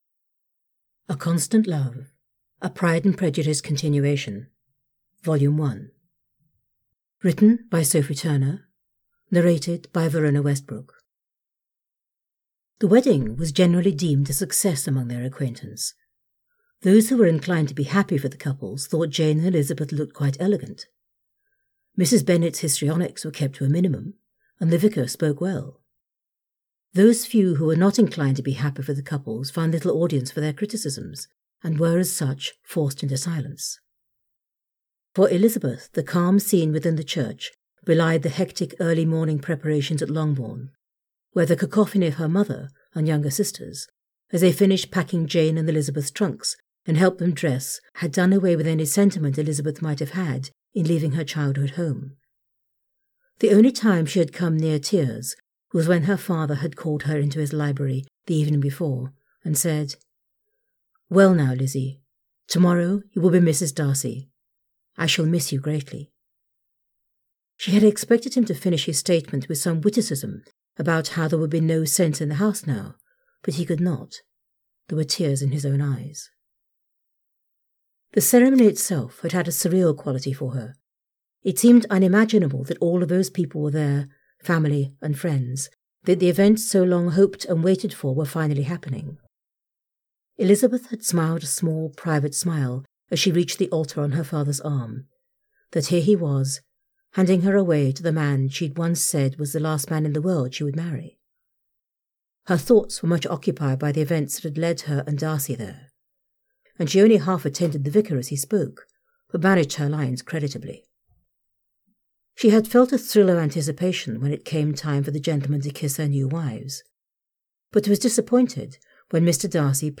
The A Constant Love audiobook is available on Amazon, Audible and iTunes.